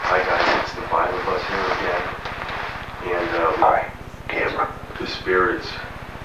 All of the investigations were conducted between 12 midnight and 3am over a two day period.
EVP's